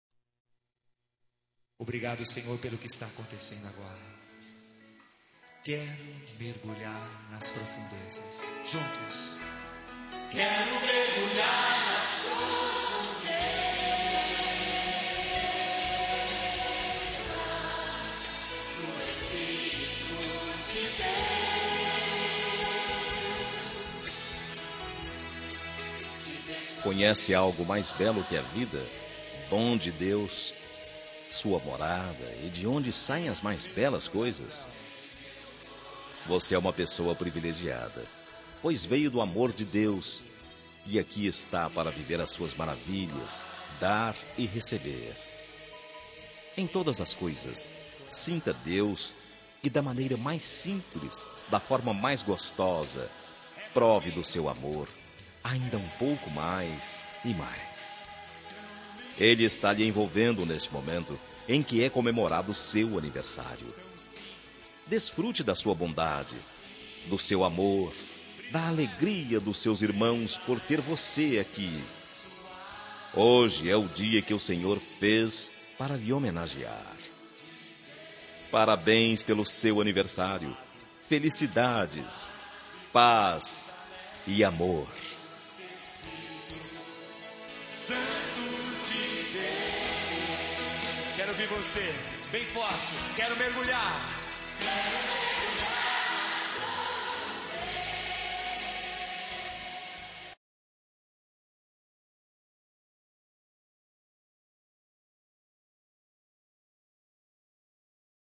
Telemensagem de Aniversário de Mãe – Voz Masculina – Cód: 1450 – Religiosa